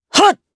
Lucias-Vox_Attack1_jp.wav